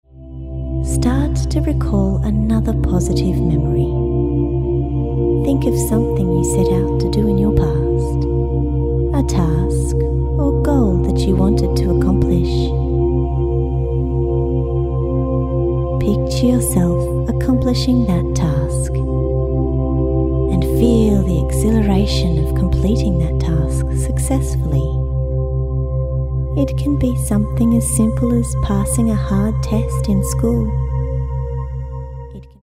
Neurolinguistic programming is incorporated into our relaxing and useful MP3 that is just under 25 minutes long.
More Self Confidence Hypnotherapy Usage